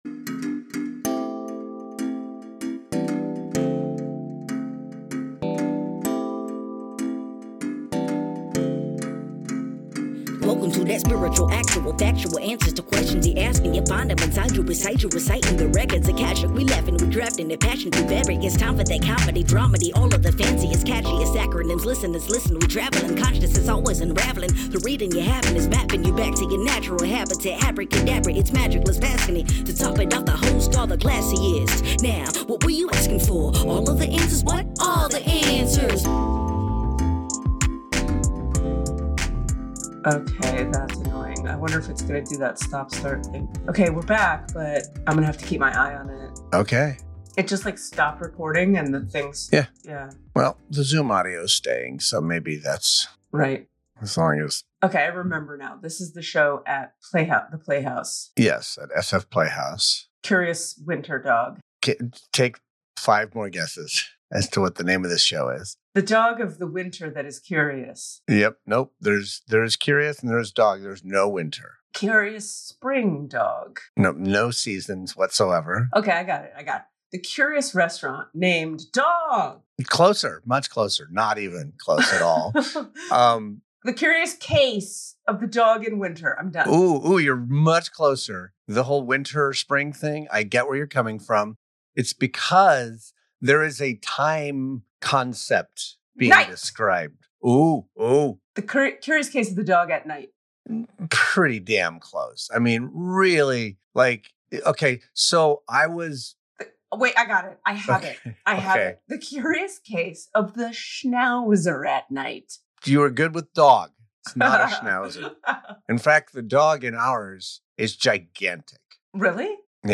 With a playful banter that keeps listeners on their toes, they explore the curious title of a play, “???????” and reflect on the frustrations of detail-oriented trivia hosts.
With humor and honesty, they encourage listeners to embrace their own quirks and to find joy in the messiness of life.